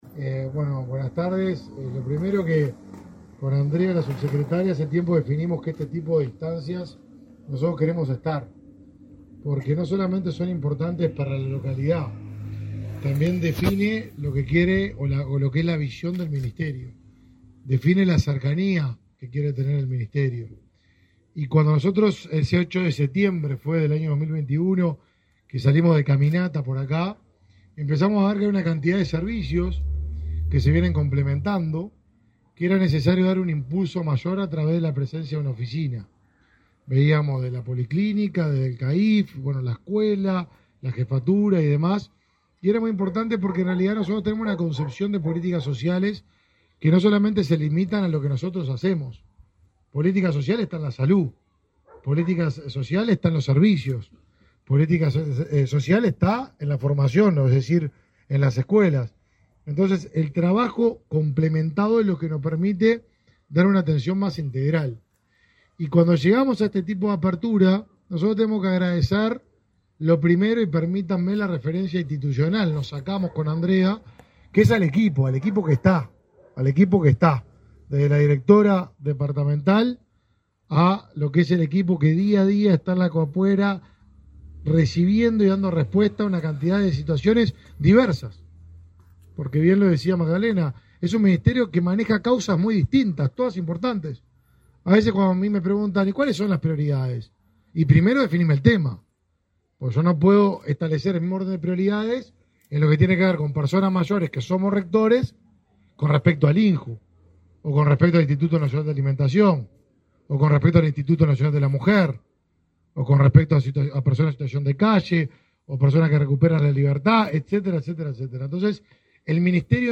Palabras del ministro de Desarrollo Social, Martín Lema
El titular del Ministerio de Desarrollo Social (Mides), Martín Lema, inauguró, este 6 de mayo, un espacio de atención descentralizado del Mides en la